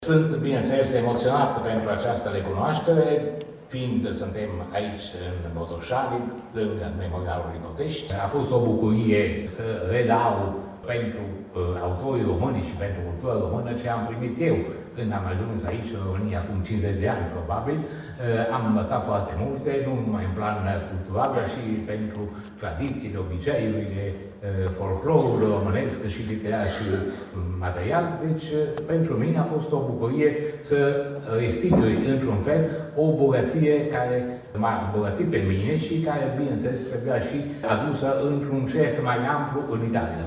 La Teatrul Mihai Eminescu din Botoșani a avut loc aseară Gala Culturii Naționale.